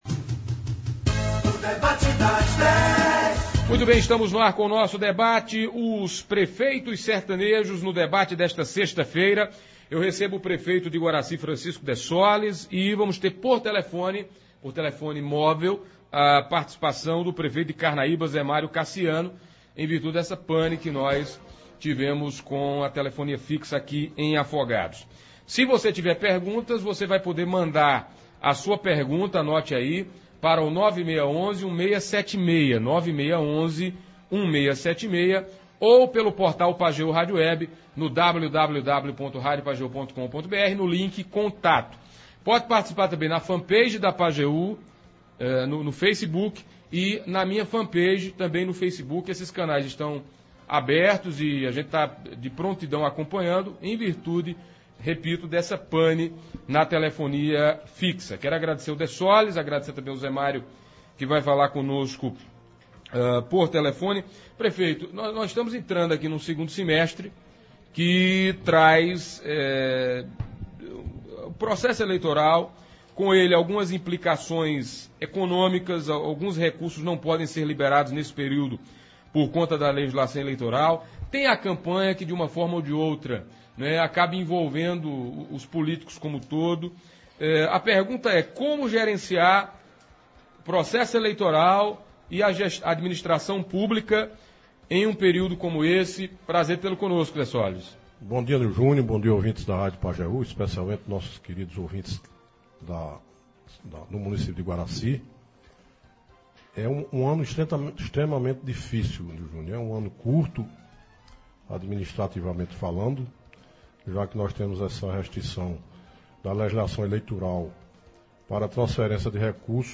Na Manhã de ontem (18), os prefeitos de Iguaracy, Francisco Dessoles, nos estúdios da Pajeú e o prefeito de Carnaíba, José Mário Cassiano, por telefone, falaram das dificuldades que o segundo semestre de ano eleitoral trazem às administrações públicas.